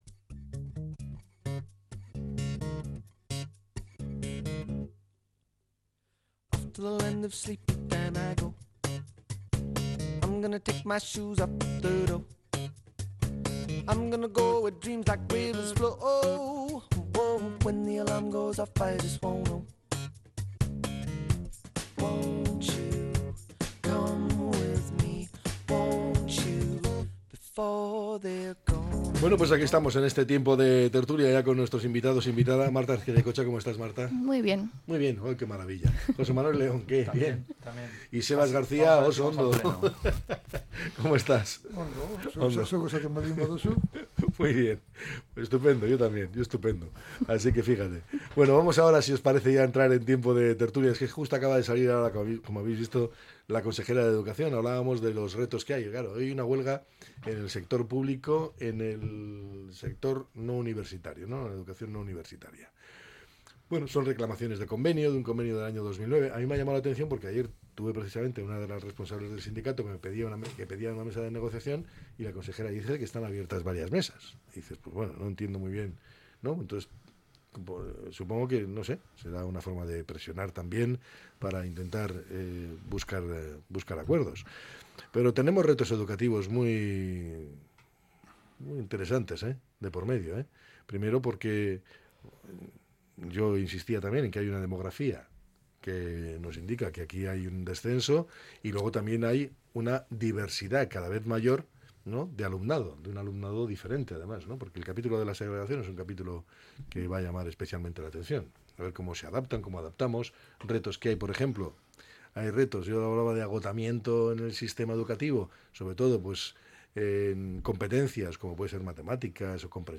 La tertulia 22-01-25.